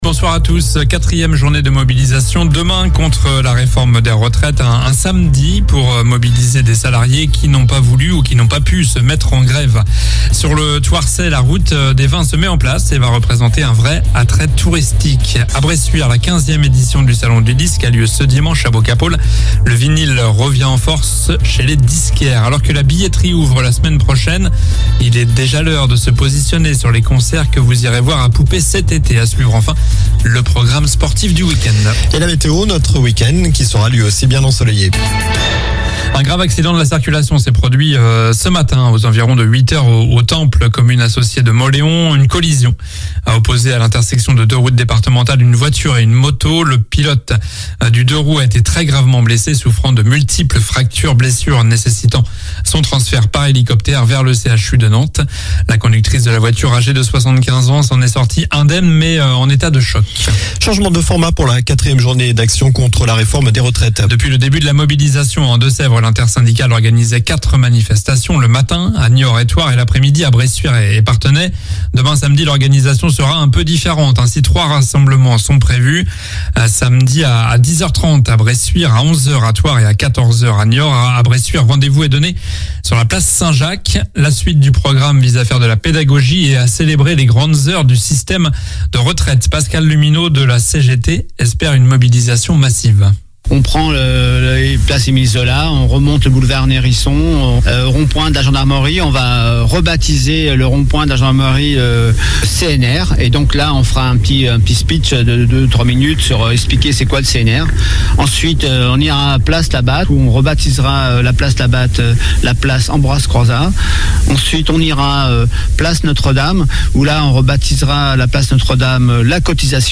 Journal du vendredi 10 février (soir)